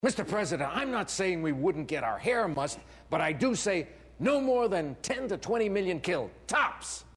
OLD MOVIE QUOTES